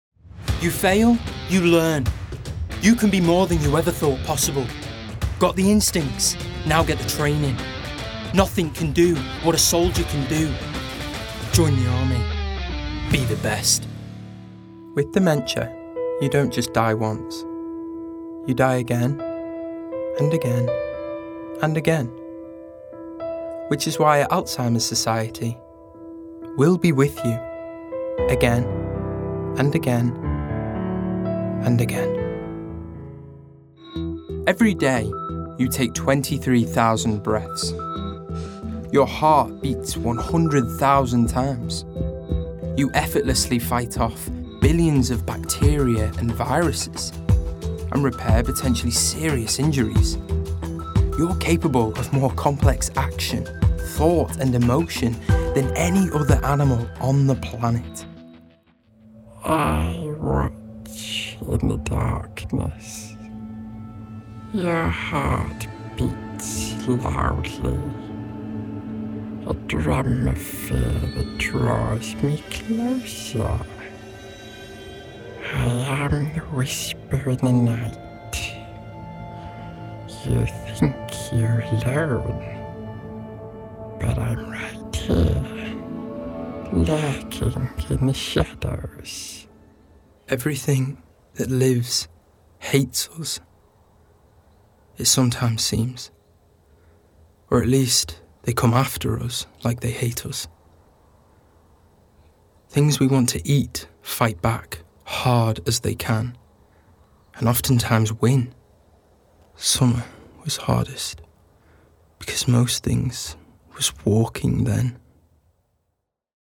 North West
Voicereel: